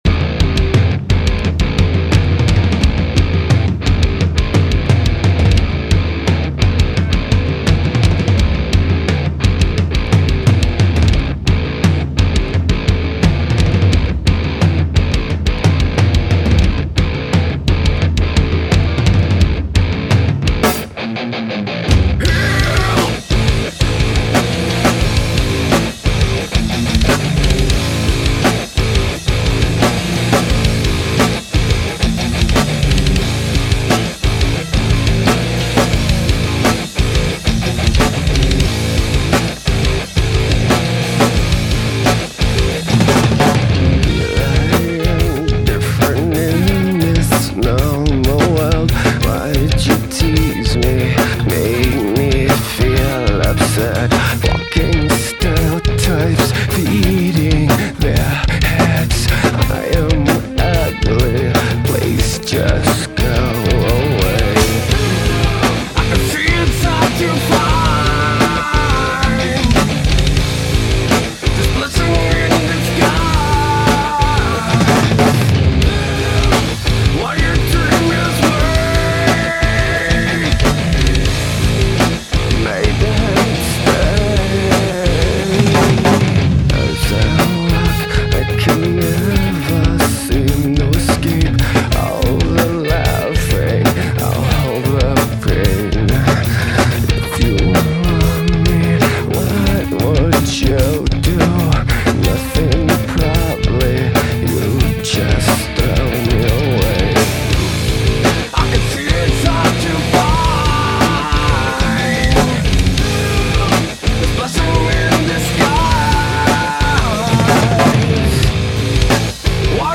Альтернативный рок Alternative rock